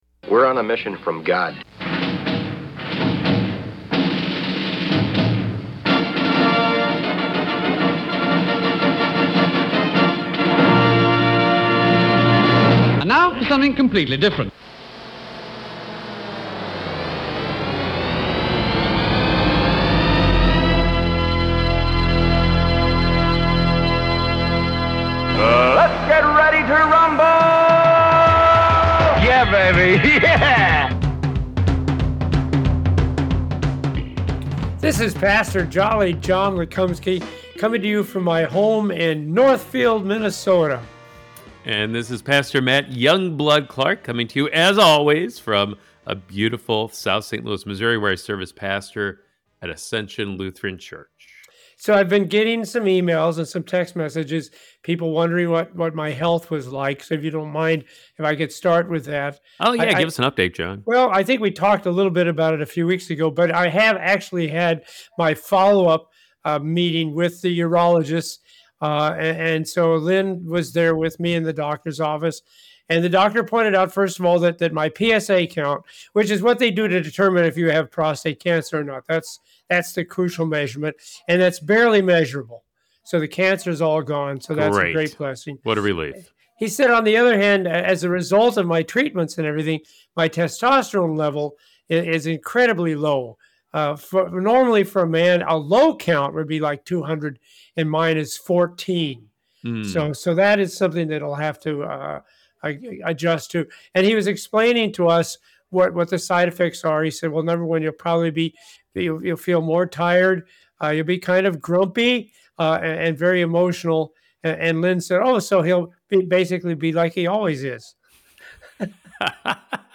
as they take a humorous approach to Bible Study